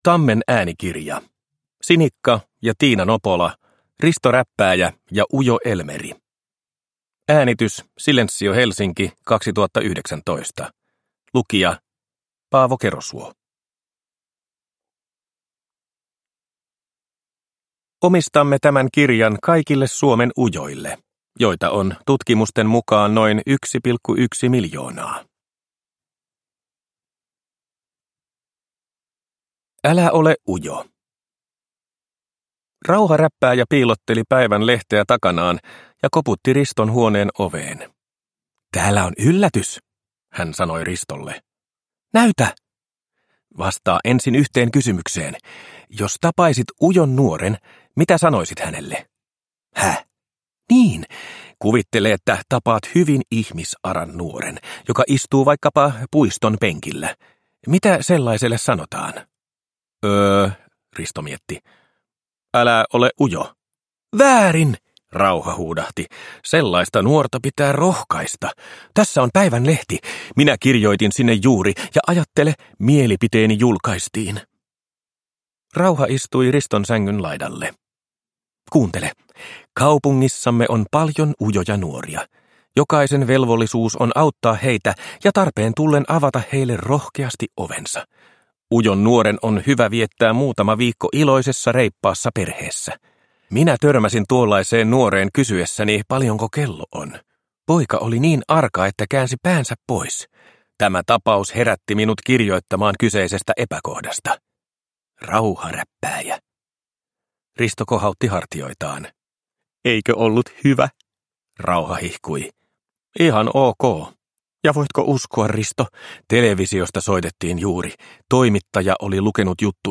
Risto Räppääjä ja ujo Elmeri – Ljudbok – Laddas ner